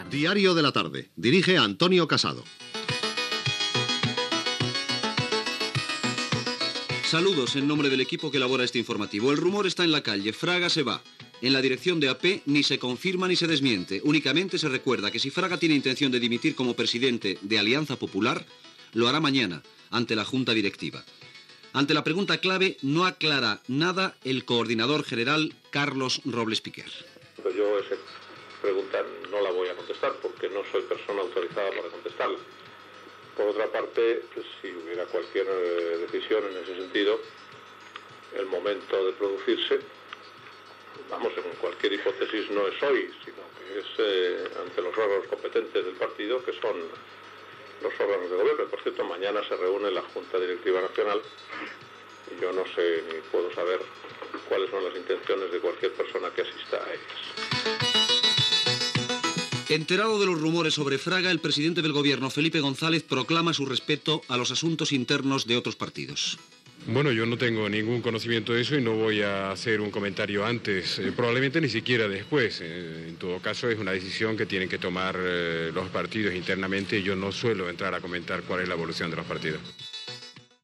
Identificació del programa, Manuel Fraga Iribarne pot ser que dimiteixi com a líder d'Alianza Popular, declaracions de Carlos Robles Piquer (AP) i Felipe González (PSOE)
Informatiu